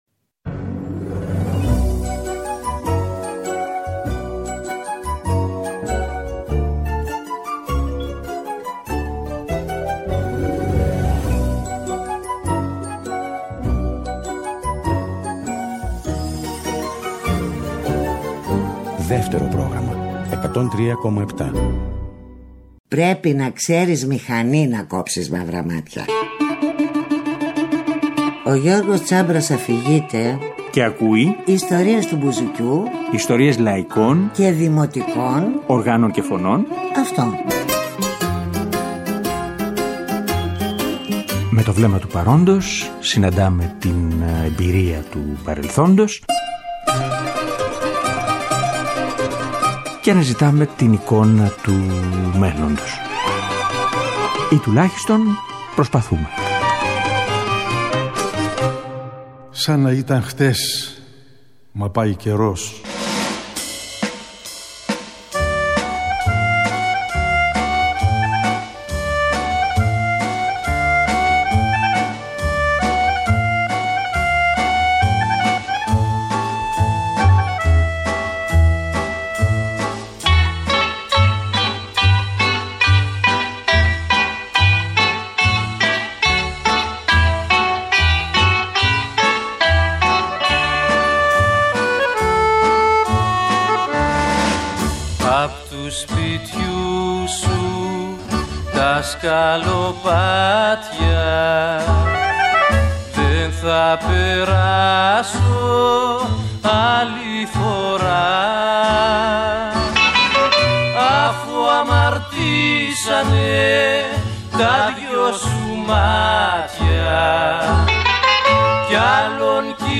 Ξανακούγοντάς τις αφηγήσεις, τις σκέψεις και τις εκτιμήσεις του Νίκου Ξανθόπουλου από τότε, διαπίστωσα ότι σε πολλά σημεία μας αφορούν και σήμερα. Και πιο πολύ αυτό που ήθελα, ήταν να ξανακουστεί η χροιά της φωνής του, ο τρόπος σκέψης και συζήτησης.
Και φυσικά, ανάμεσα σ’ αυτά και τα τραγούδια του, ίσως διαβάσουμε και κάποια μικρά αποσπάσματα από όσα γράφτηκαν τελευταία ή από όσα έγραψε ο ίδιος μετά!